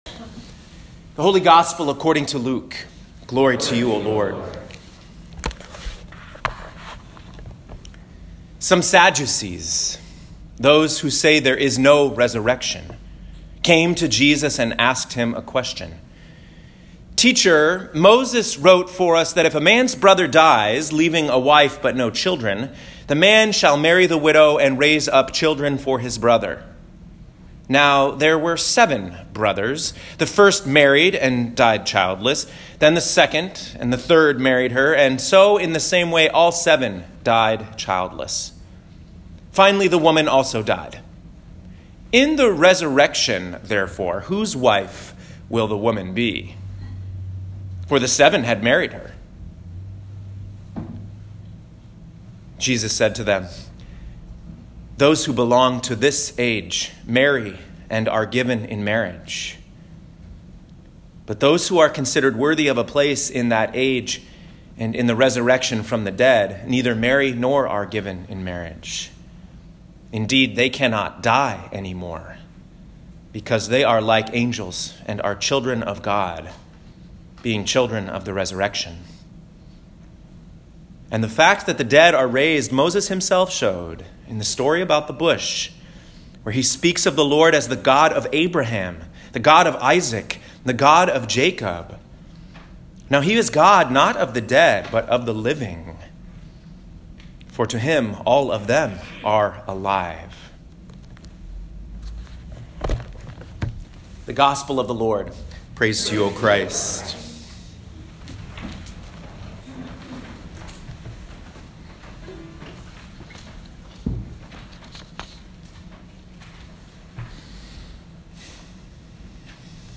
Twenty-Second Sunday after Pentecost, Year C (11/10/2019) Job 19:23-27a Psalm 17:1-9 2 Thessalonians 2:1-5, 13-17 Luke 20:27-38 Click the play button to listen to this week’s sermon.